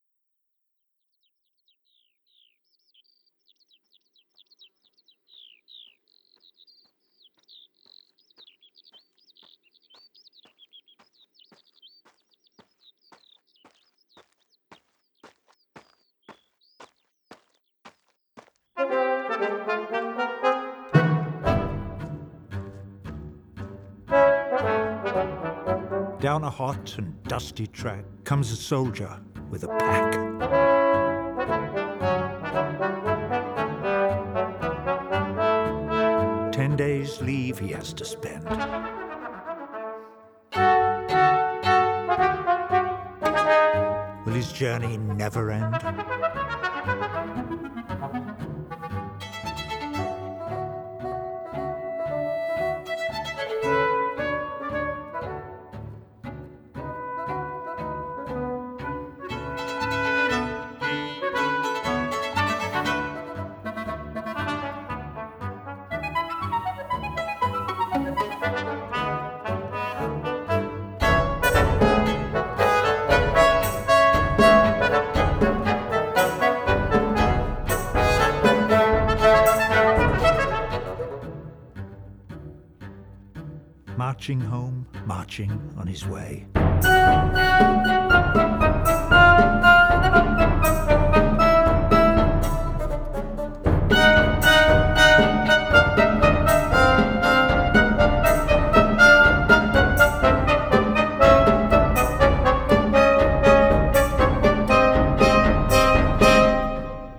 Classical, Opera, Spoken Word, Avant-garde